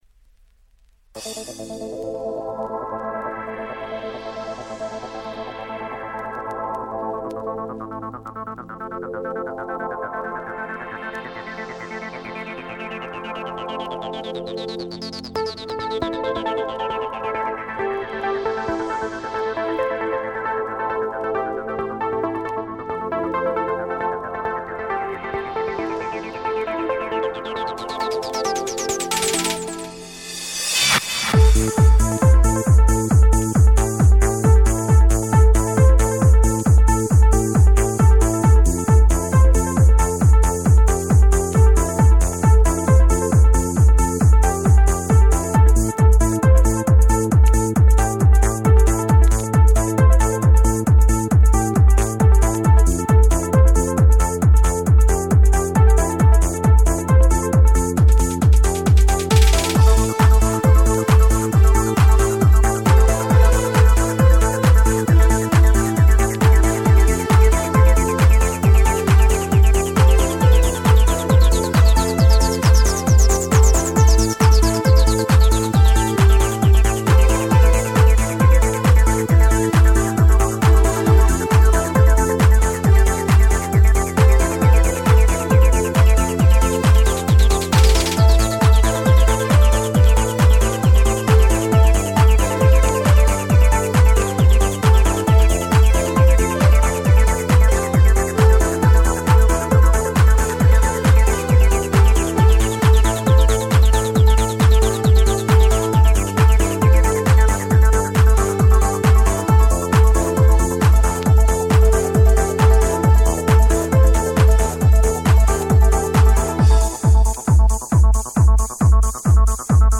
Жанр: Dream, Trance